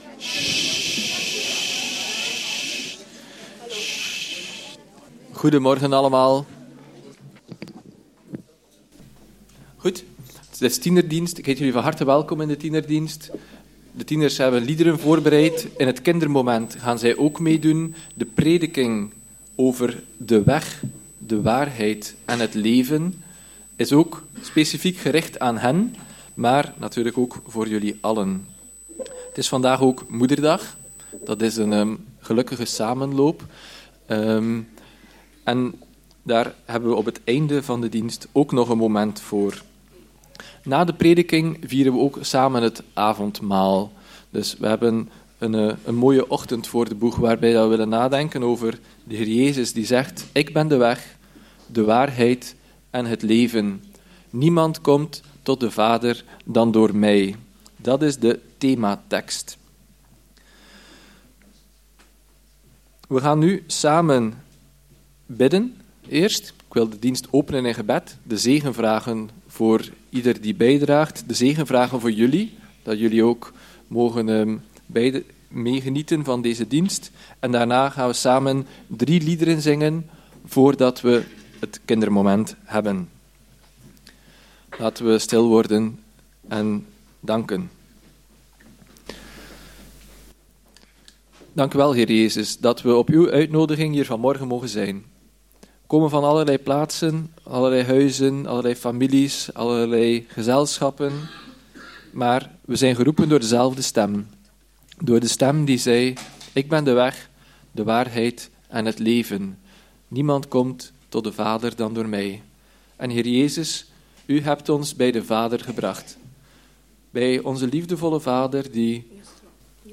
Joh. 13:31 tot Joh. 14:14 Dienstsoort: Tienerdienst Bestaat dé Weg